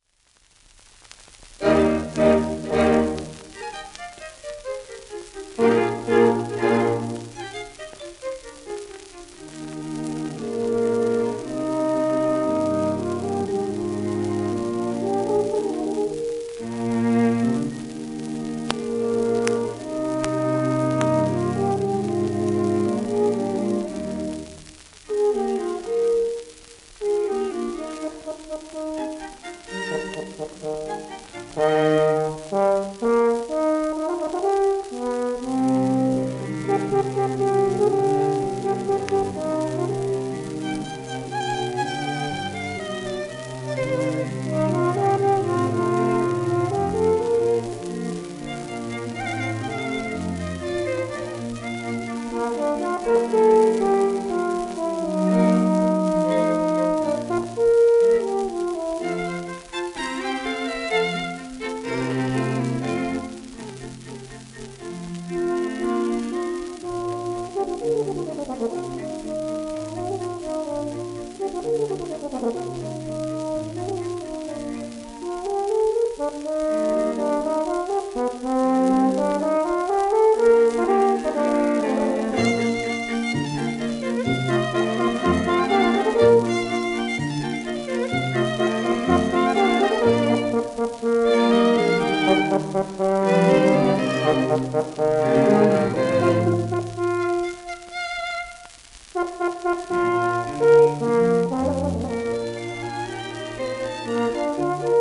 1944年頃録音